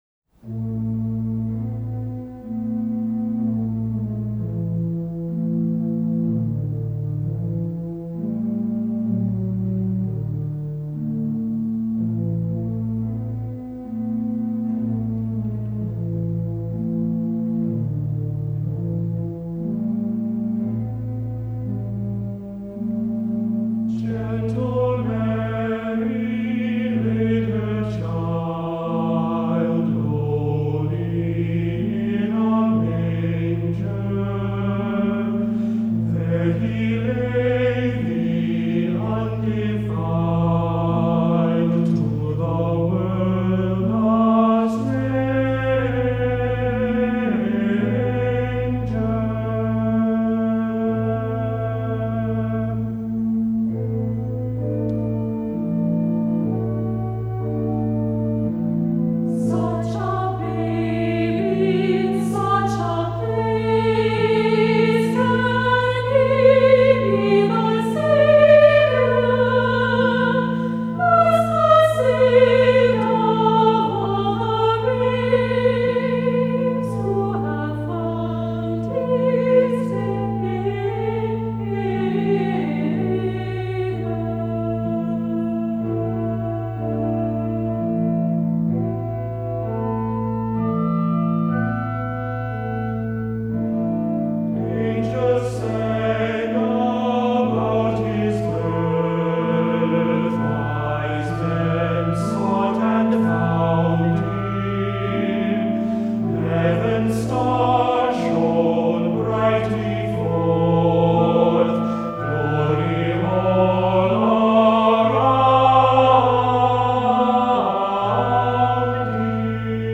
Voicing: 2-part Choir